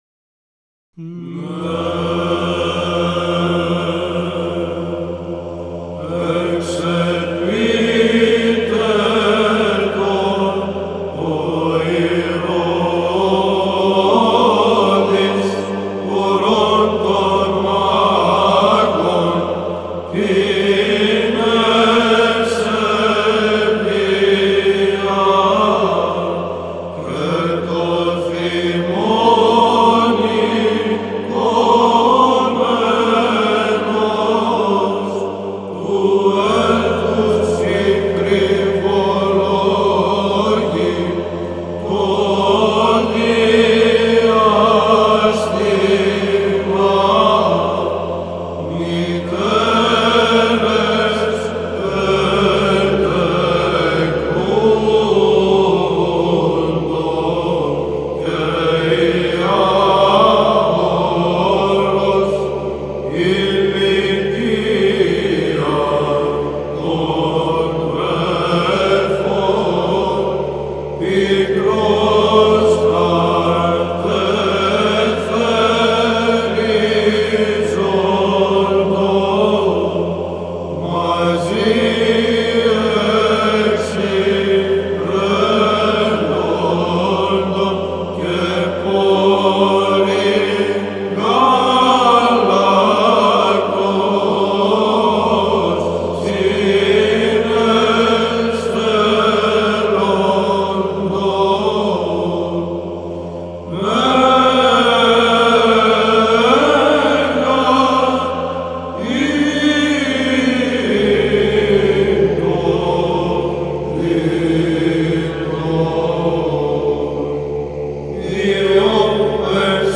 ΒΥΖΑΝΤΙΝΟΙ ΥΜΝΟΙ ΧΡΙΣΤΟΥΓΕΝΝΩΝ
ιδιόμελον Θ΄ ώρας, ήχος βαρύς